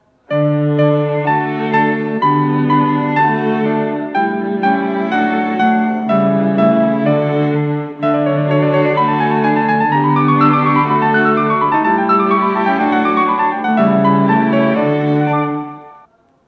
クラシック
使用駅：神立（＃１）・土浦（＃１）・荒川沖（＃１）